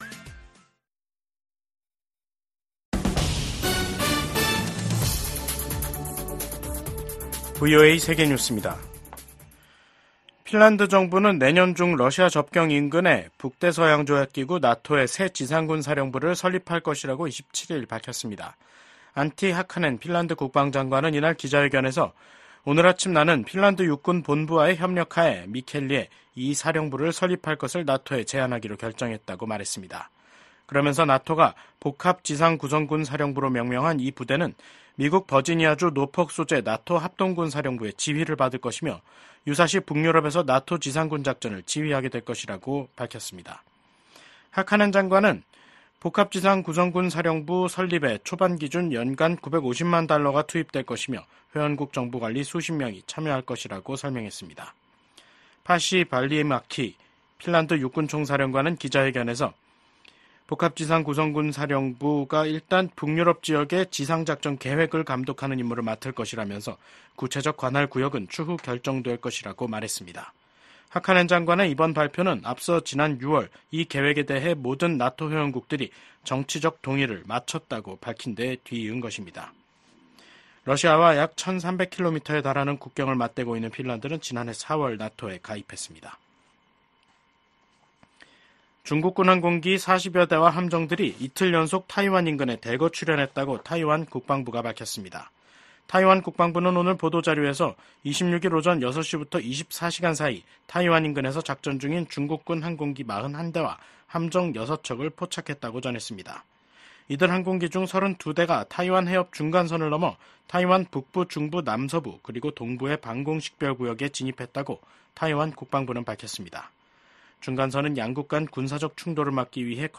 VOA 한국어 간판 뉴스 프로그램 '뉴스 투데이', 2024년 9월 27일 3부 방송입니다. 미국 대북 정책의 주요 요소는 종교와 신앙의 자유에 대한 정보를 포함한 북한 내 정보 접근을 확대하는 것이라고 미국 북한인권특사가 밝혔습니다. 미국과 영국, 호주의 안보협의체인 오커스가 첨단 군사기술 개발 협력 분야에서 한국 등의 참여 가능성을 논의 중이라고 확인했습니다.